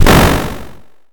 Explode_04.mp3